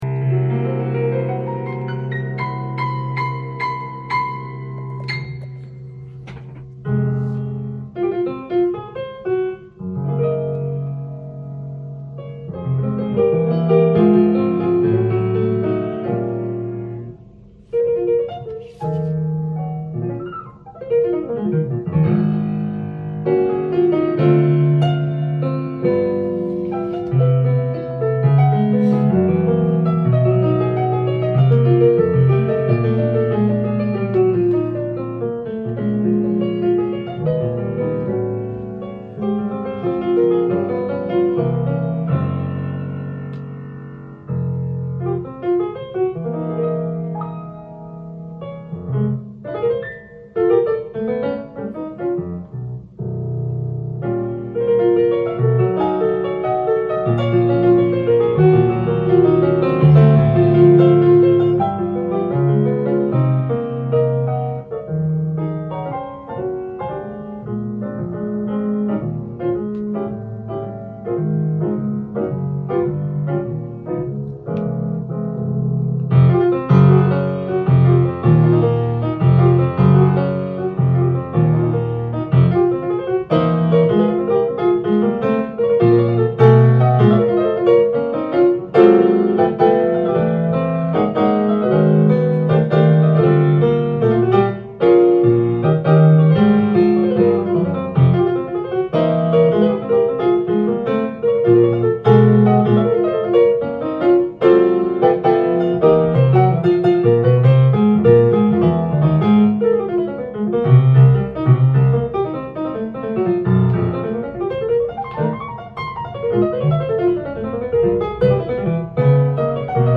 店頭で録音した音源の為、多少の外部音や音質の悪さはございますが、サンプルとしてご視聴ください。
ソロフォーマットでも抜群に躍動的な音を聴かせます。